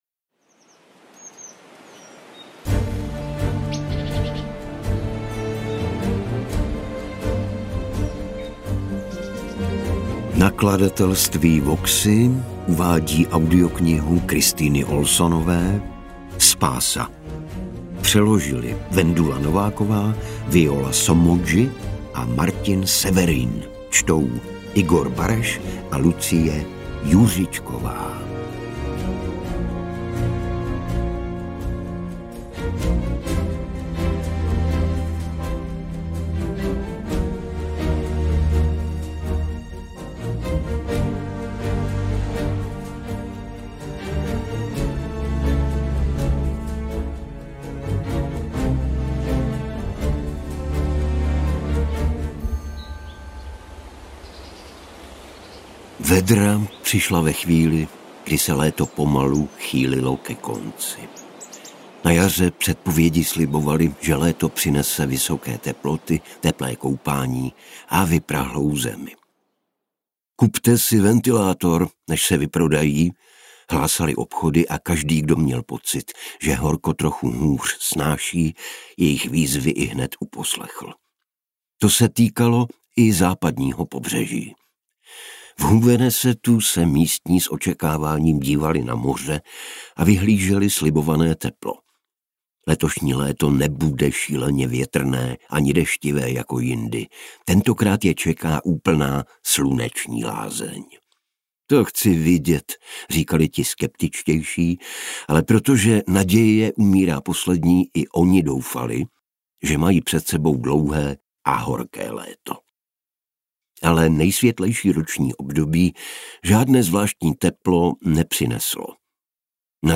Interpreti:  Igor Bareš, Lucie Juřičková
AudioKniha ke stažení, 37 x mp3, délka 17 hod. 40 min., velikost 968,2 MB, česky